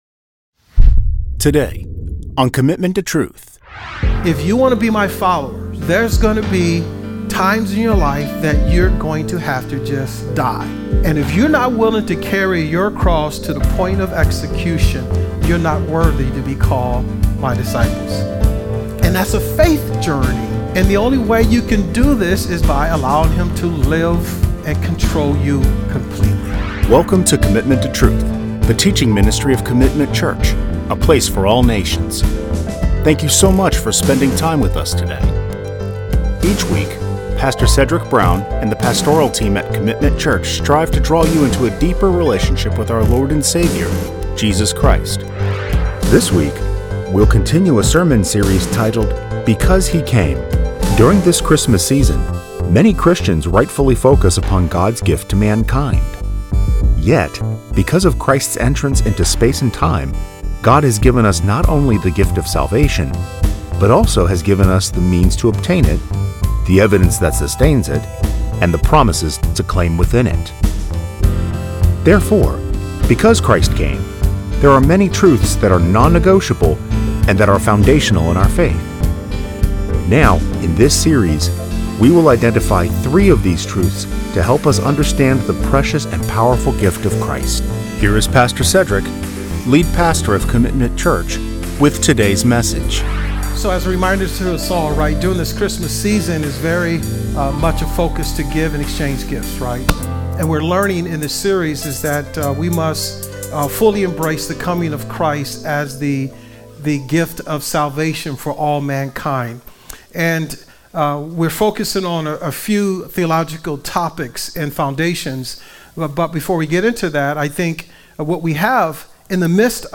Sermons | Commitment Community Church